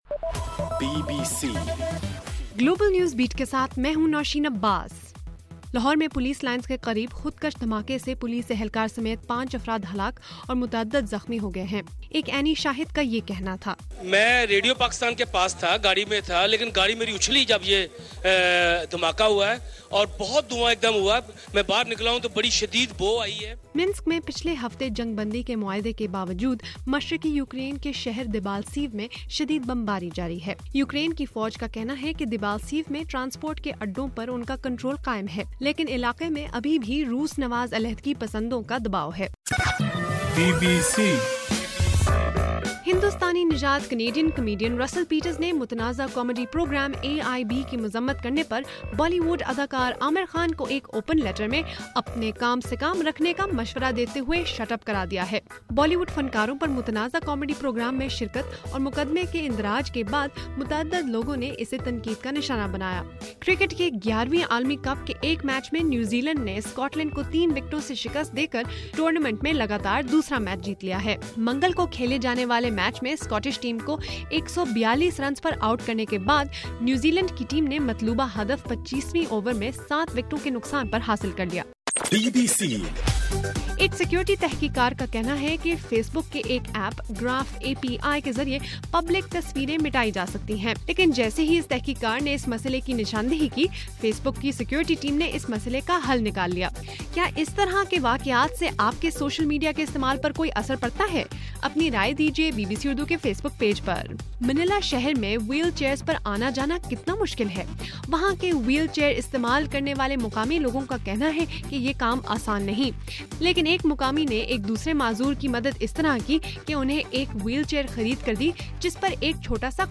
فروری 17: رات 8 بجے کا گلوبل نیوز بیٹ بُلیٹن